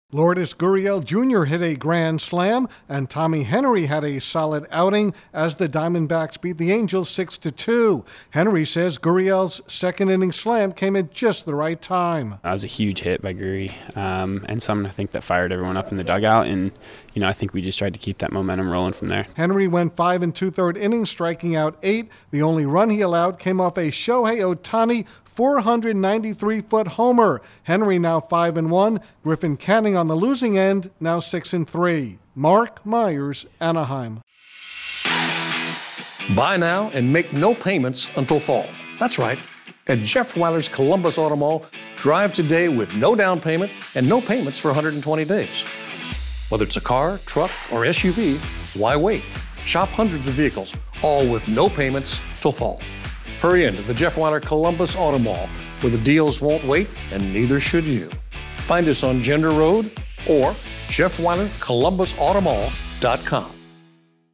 An early blast sends the Diamondbacks past the Angels. Correspondent